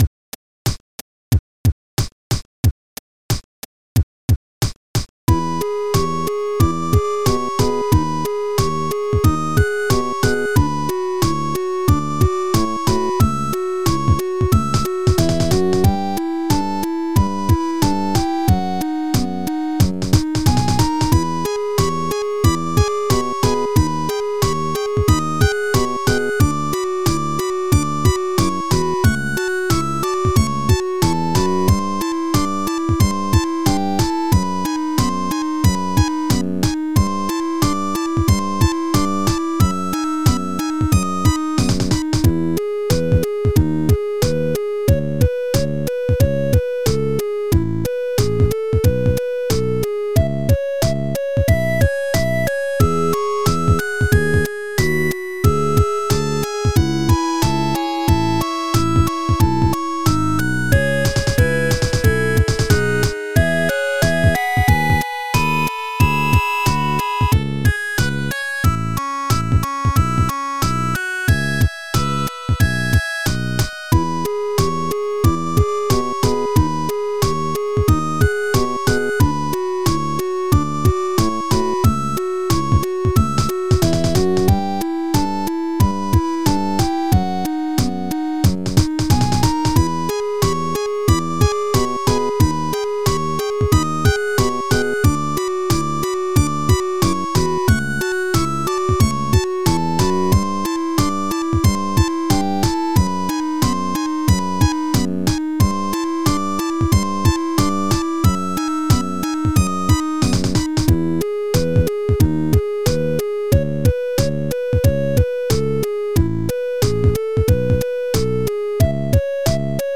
181bpm